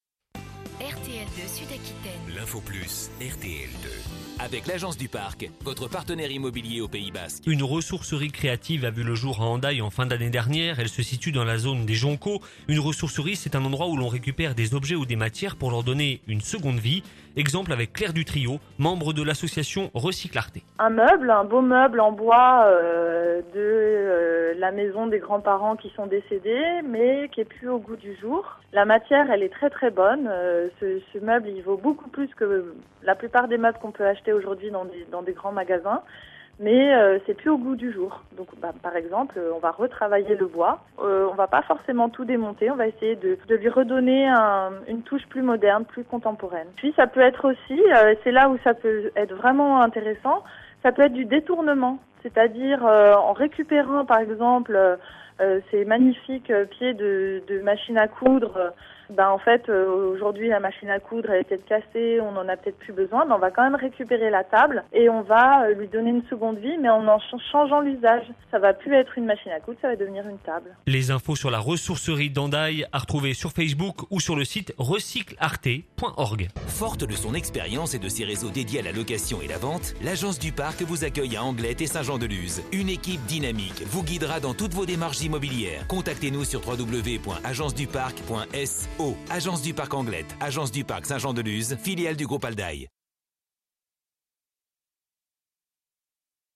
interview RTL2 RECYCLERIE HENDAYE 12 JAN 2017